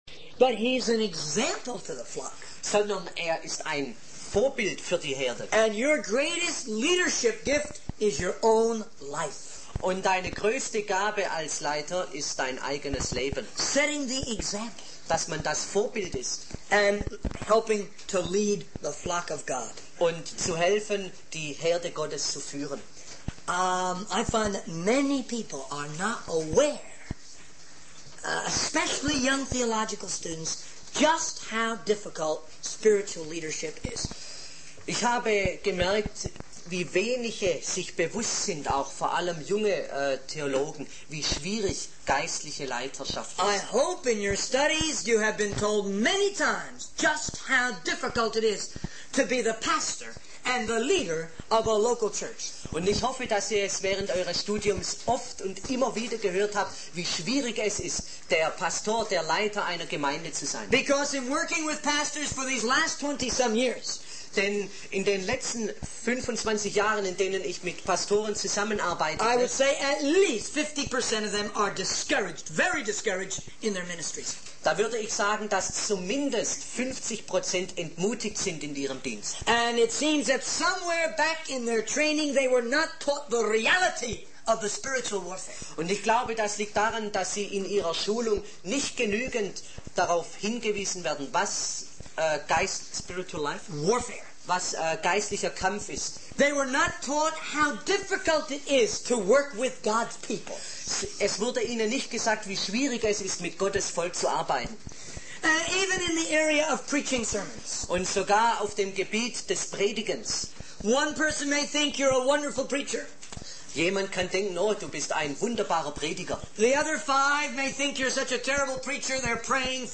In this sermon, the speaker discusses the importance of understanding how God guides different people in different ways. He emphasizes that God does not destroy our humanity when guiding us and that sometimes people find the will of God in unexpected ways.